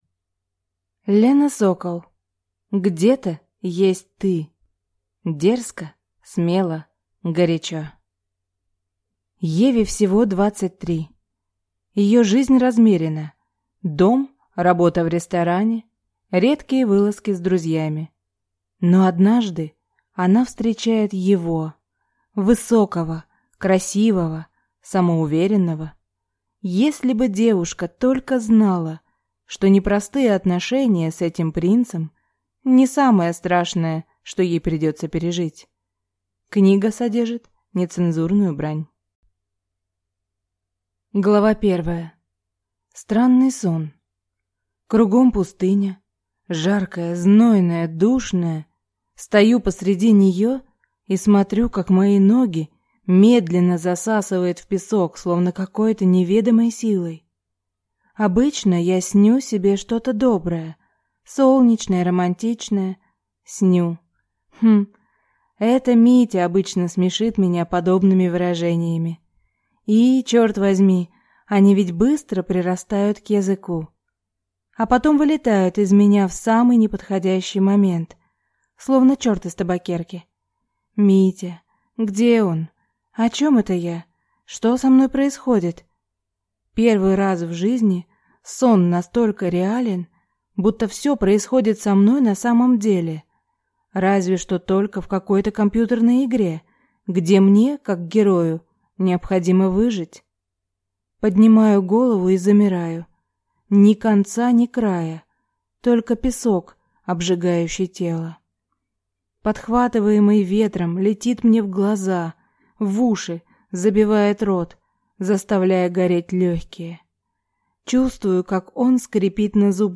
Аудиокнига Где-то есть Ты | Библиотека аудиокниг